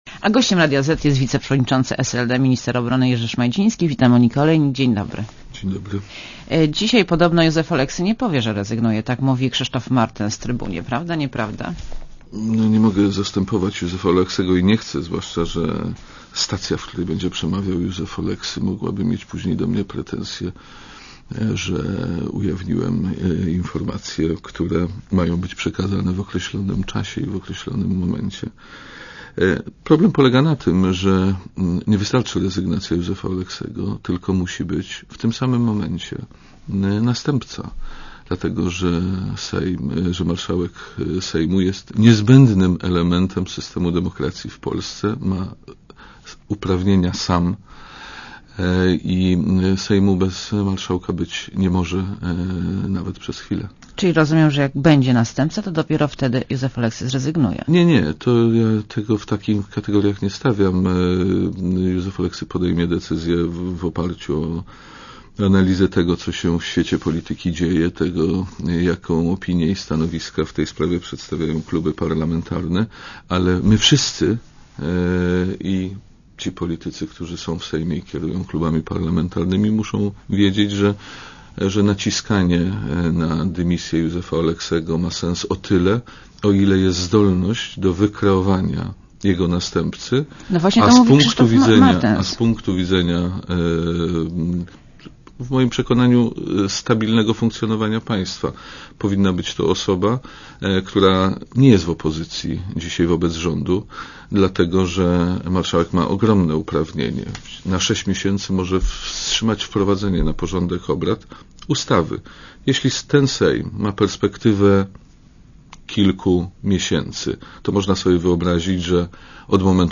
Gościem Radia Zet jest wiceprzewodniczący SLD, minister obrony narodowej Jerzy Szmajdziński. Wita Monika Olejnik.